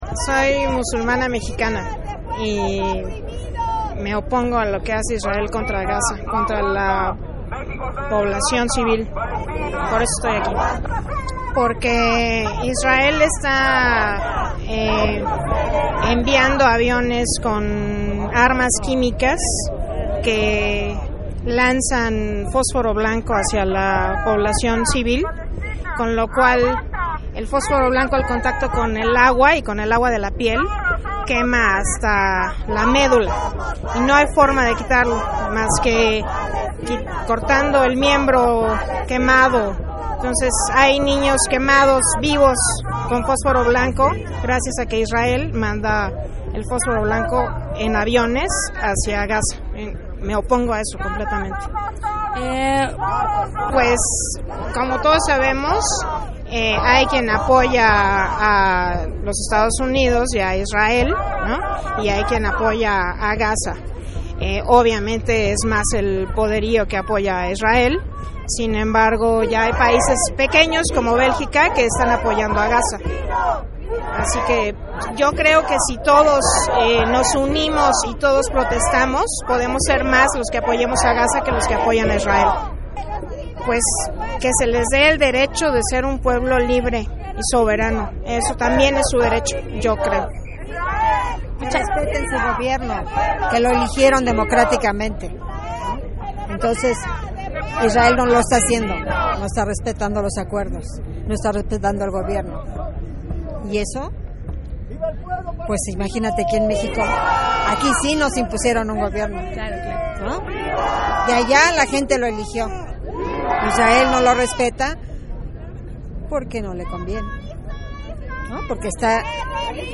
Entrevista compañera Musulmana Mexicana